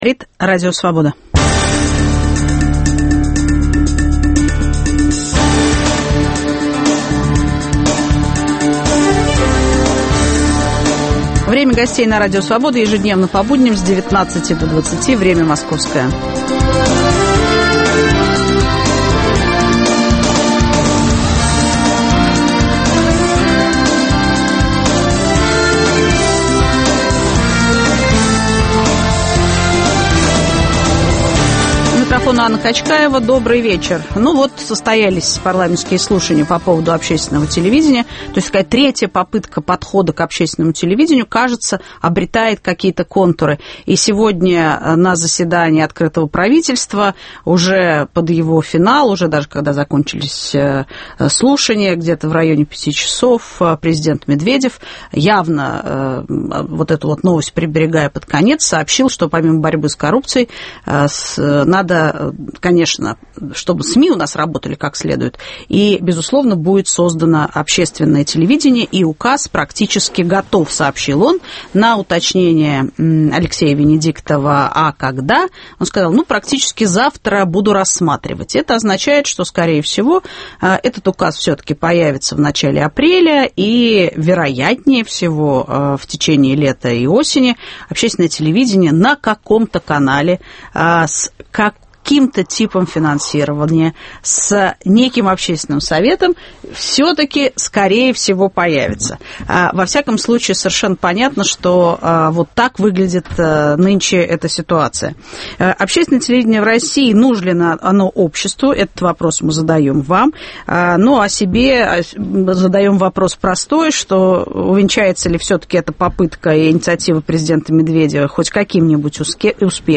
После парламентских слушаний об общественном вещании в студии - депутат ГД и член рабочей группы по ОТВ при Совете по правам человека Илья Пономарев.